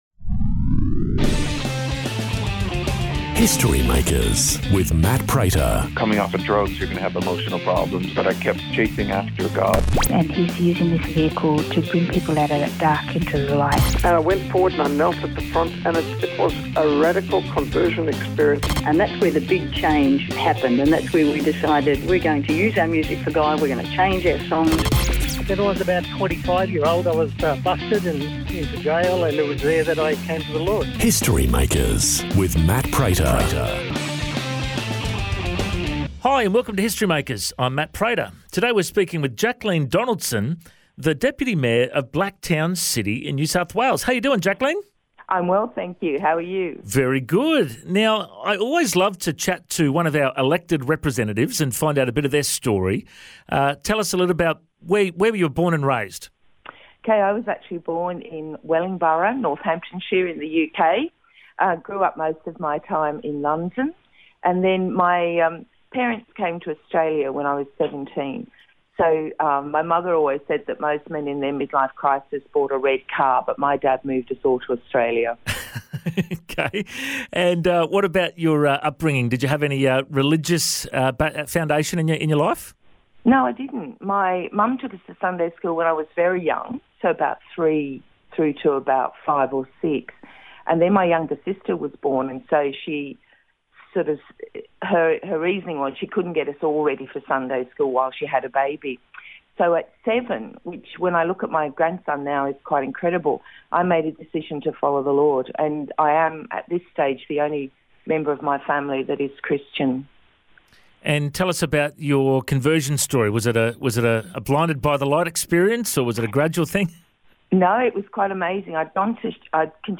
Interview, Testimony